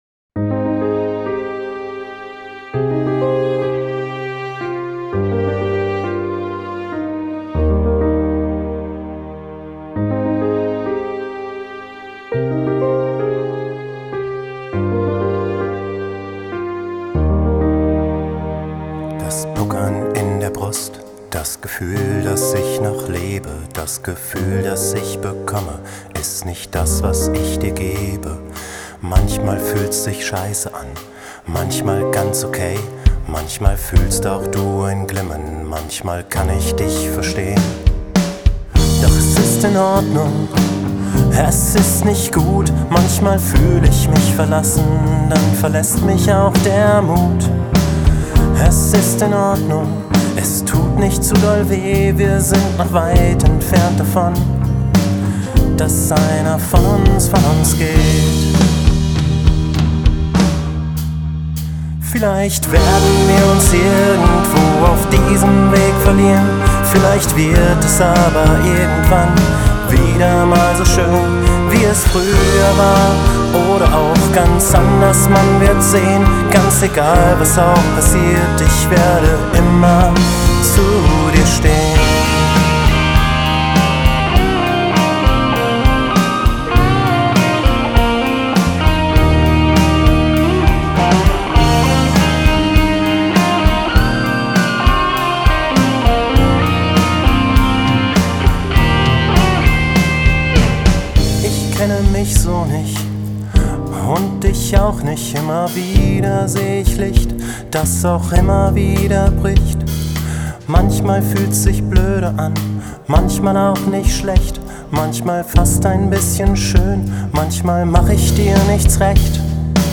Skizze - Traurige Ballade
Sympathisches Lied.
Reverbs alle ein Stückchen zurückgefahren, Snare weniger, Drums insgesamt ein Stückchen weniger. Basslauf in der Strophe etwas verändert.